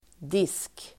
Uttal: [dis:k]